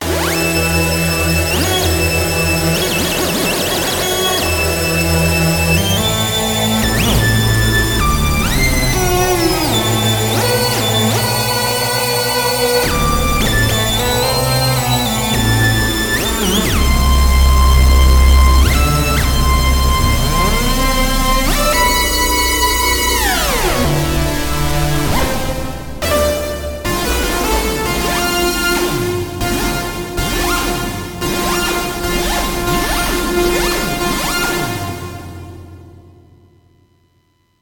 PitchADSR1-Part3 (DEMO Audio)